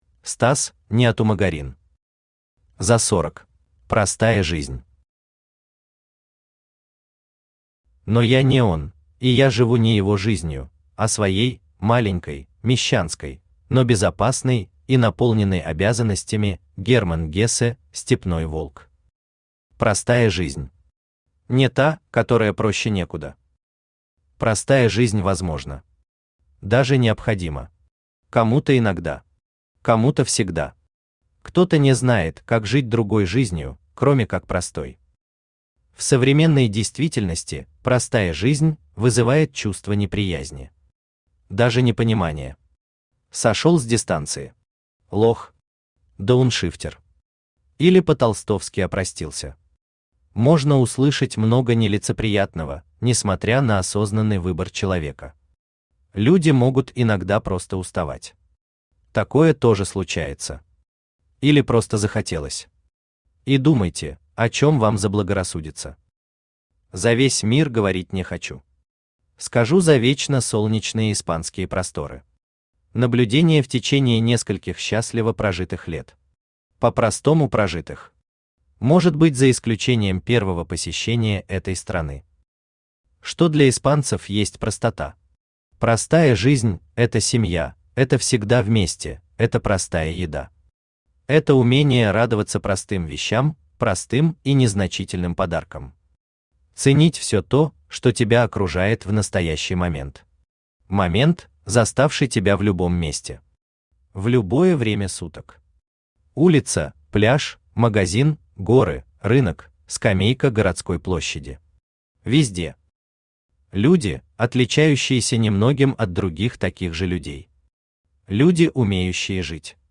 Aудиокнига За сорок Автор Стас Неотумагорин Читает аудиокнигу Авточтец ЛитРес.